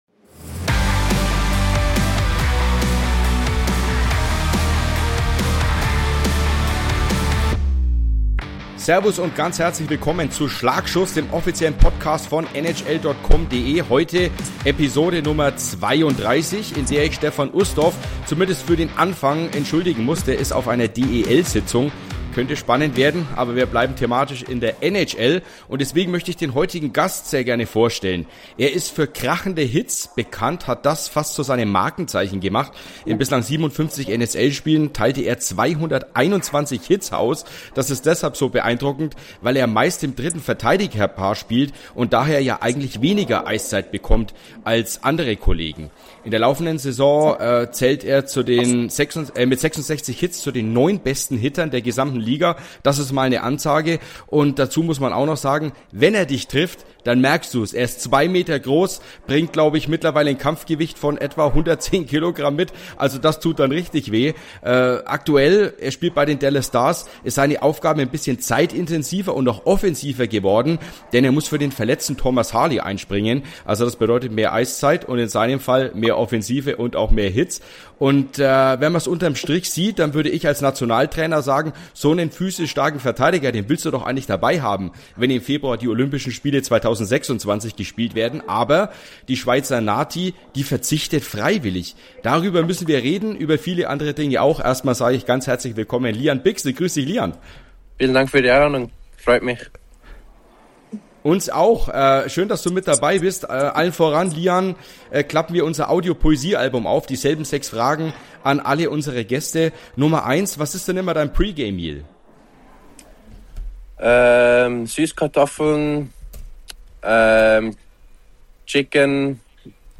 Mit dem Schweizer Lian Bichsel ist einer der besten NHL-Hitter zu Gast und spricht über den Energieschub seiner Checks für die Dallas Stars, seine Beziehung zu Torwart Jake Oettinger und die Hoffnung auf eine Begnadigung vom Schweizer Verband.